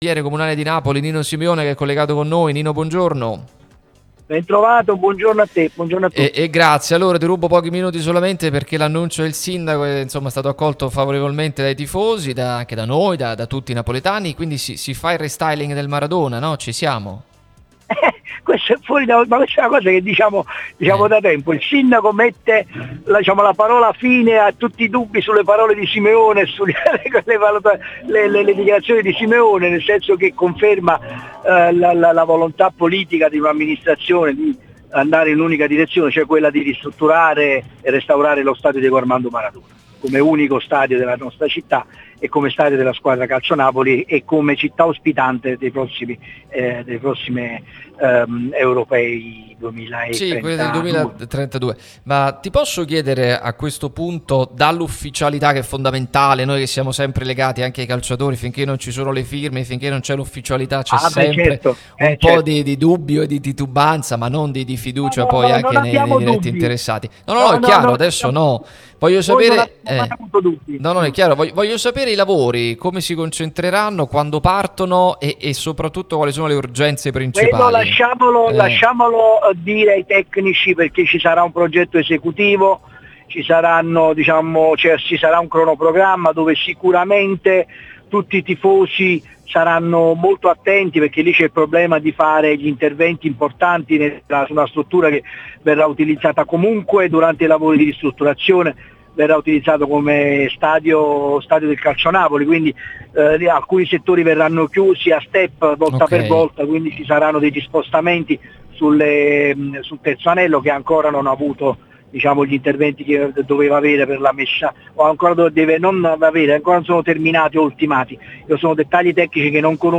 Nino Simeone, consigliere comunale e presidente della commissione trasporti, infrastrutture e lavori pubblici, è intervenuto su Radio Tutto Napoli, prima radio tematica sul Napoli, che puoi ascoltare/vedere qui sul sito, in auto col DAB Campania o sulle app gratuite (scarica qui per Iphone o qui per Android): "Conferma la volontà politica dell’amministrazione di andare in un’unica direzione: ristrutturare e restaurare lo stadio Diego Armando Maradona come unico stadio della città, stadio del Calcio Napoli e sede per i prossimi Europei del 2032".